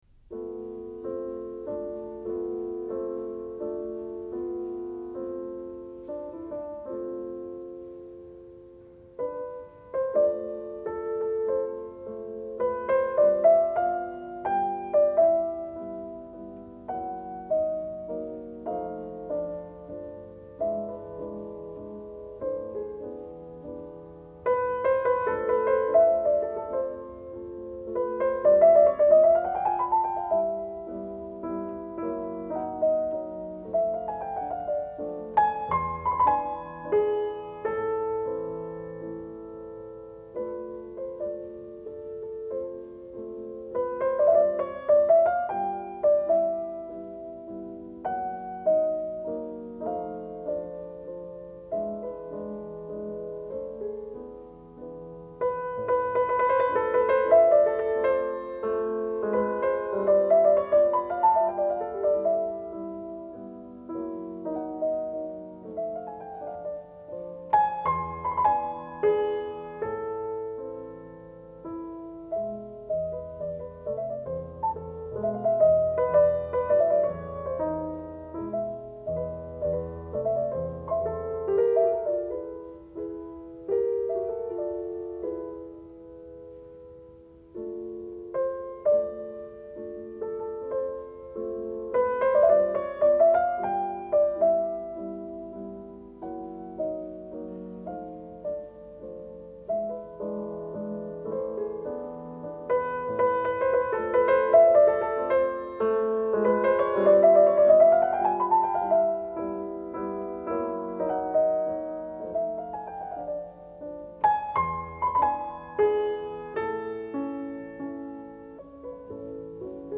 Samson François 1957 – Frédéric Chopin mazurka en la mineur Opus 17 n°4